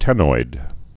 (tĕnoid, tēnoid)